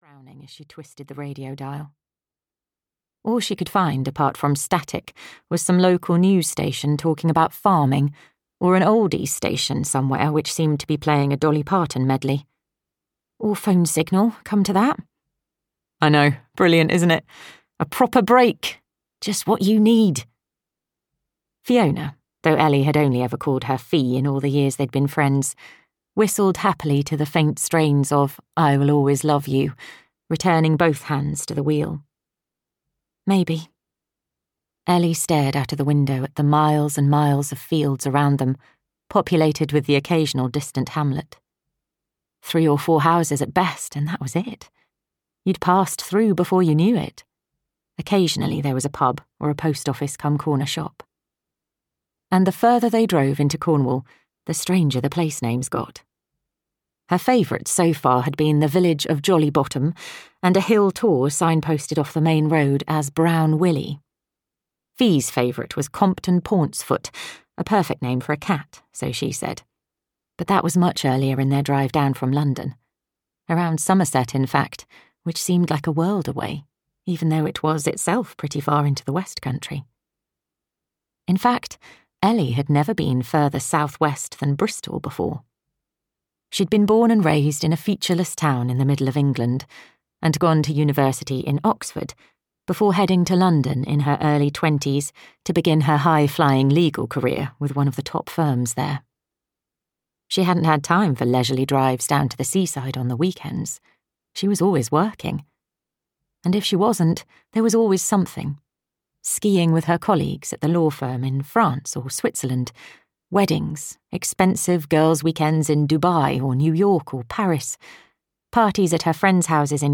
Dreams of Magpie Cove (EN) audiokniha
Ukázka z knihy